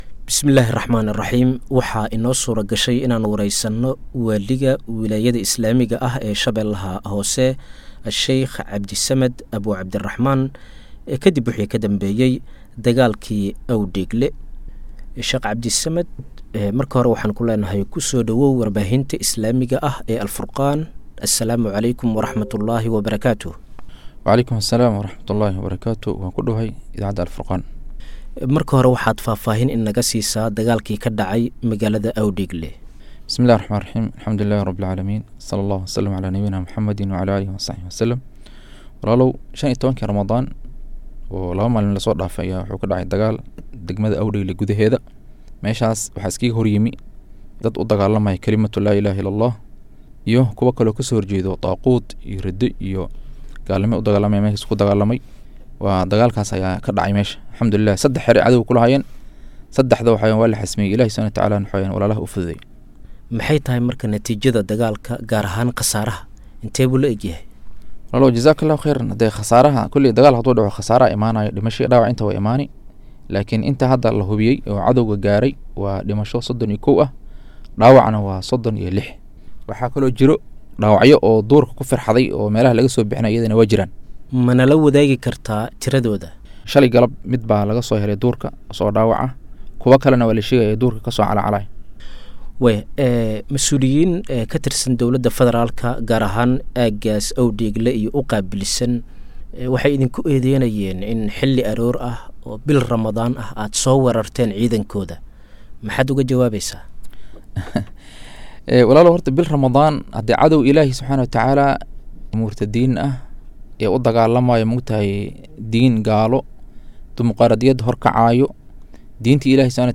Waaliga Sh-dhexe oo ka Hadlay Dagaalkii Awdheegle & Duqeymihii ka Dhacay Gobalka.[WAREYSI]